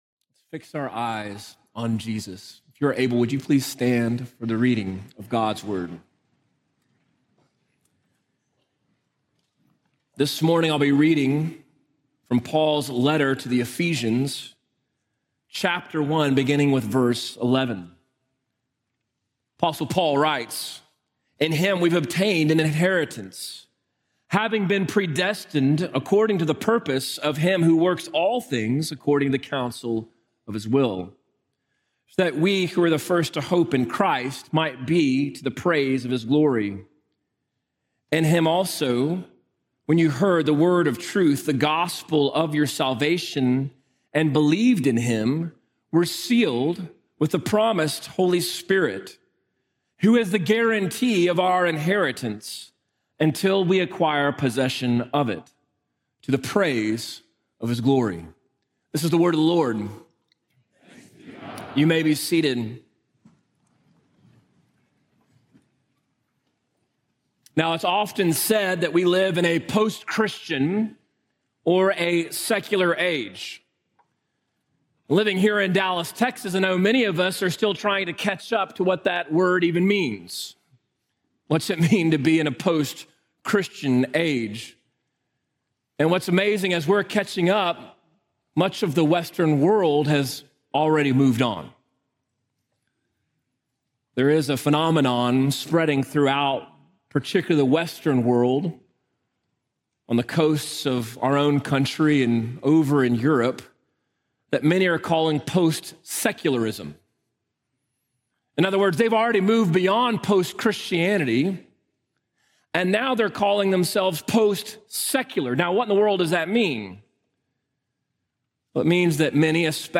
Latest Sermon